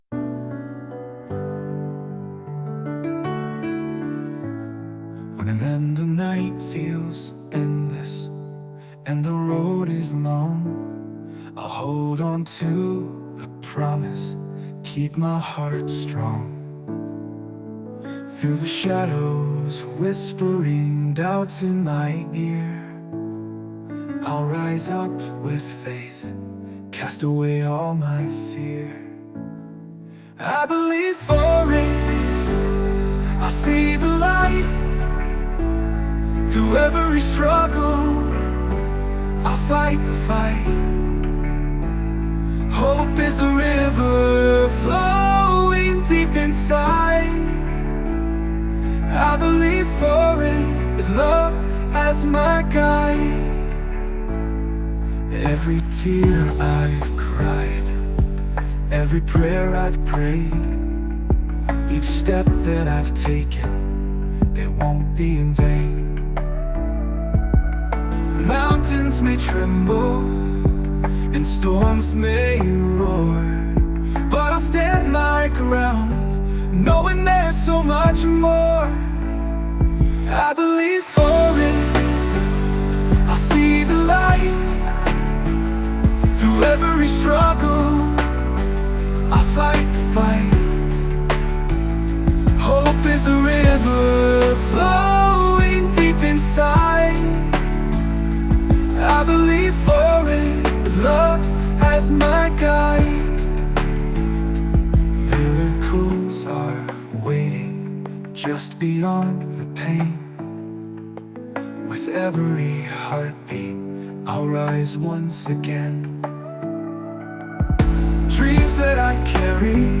blends hiphop with contemporary sounds
With its upbeat tempo and catchy sounds